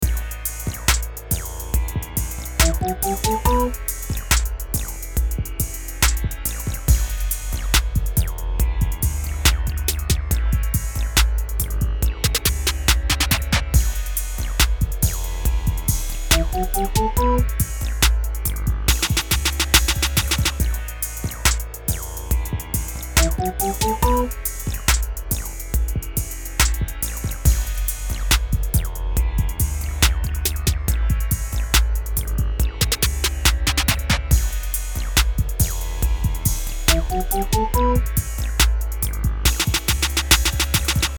テンポ70のロマンチックな着信音。優しいメロディーとハーモニーが特徴です。